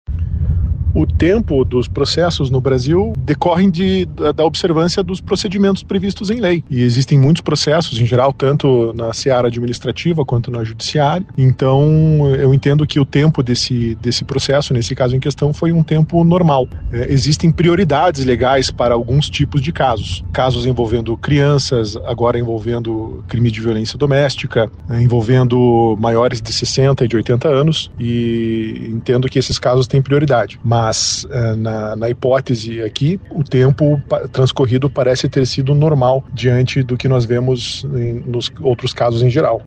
A decisão pela demissão aconteceu quatro anos após o crime e três meses após a data do júri popular, realizado na capital paranaense. O advogado especialista em direito criminal